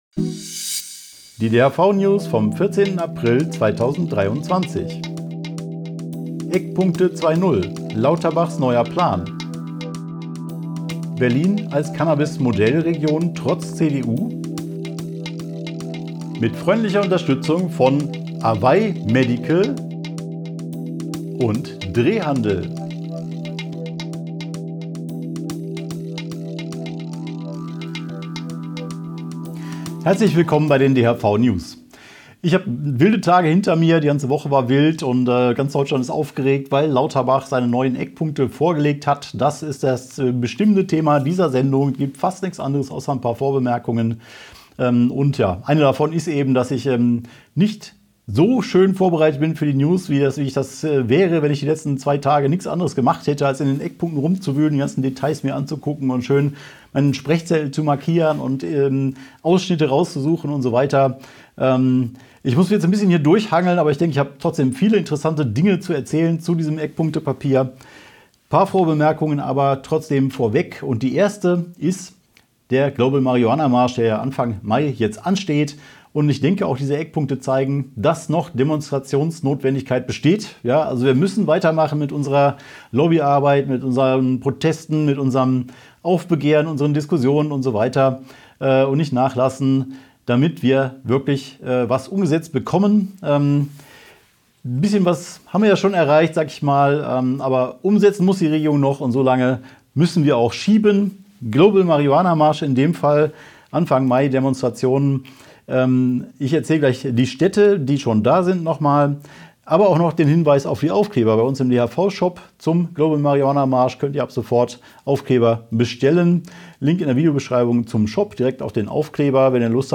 DHV-News #376 Die Hanfverband-Videonews vom 14.04.2023 Die Tonspur der Sendung steht als Audio-Podcast am Ende dieser Nachricht zum downloaden oder direkt hören zur Verfügung.